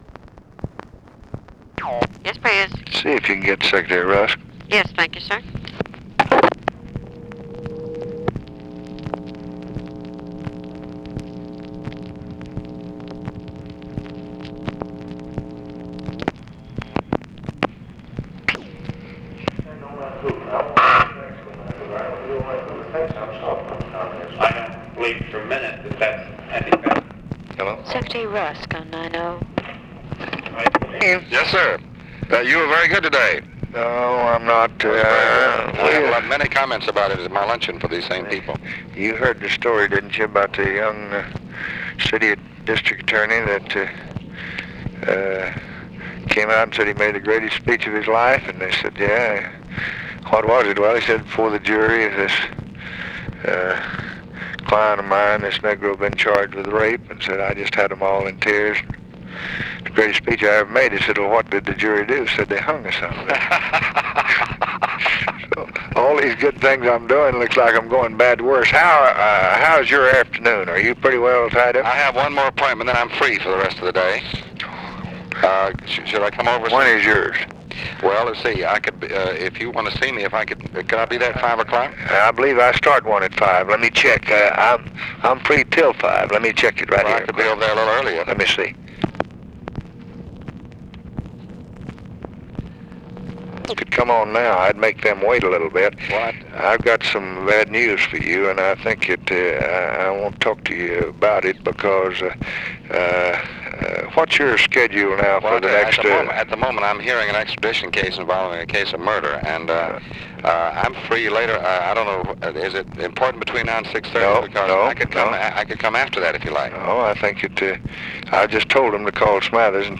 Conversation with DEAN RUSK and OFFICE CONVERSATION, October 2, 1964